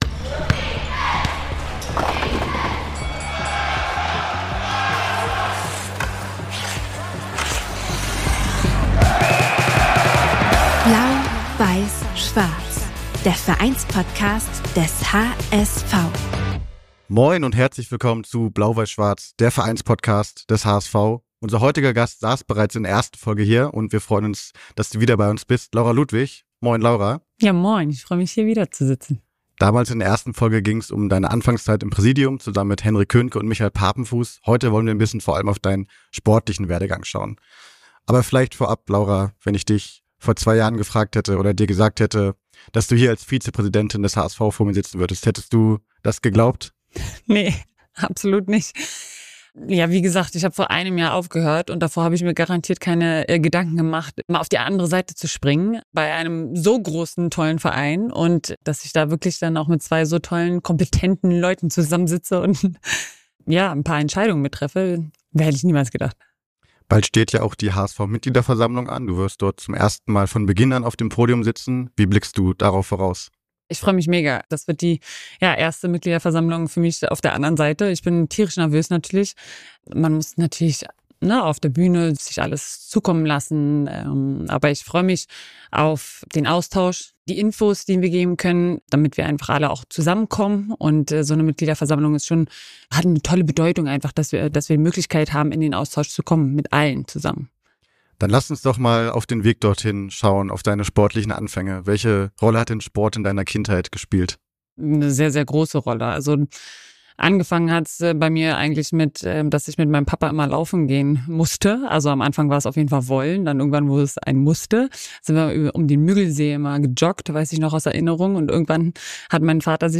In Folge 3 ist HSV-Vizepräsidentin Laura Ludwig zu Gast, die ihre internationale Karriere als erfolgreiche Beachvolleyballerin Revue passieren lässt und über ihre Tätigkeiten nach der aktiven Laufbahn spricht.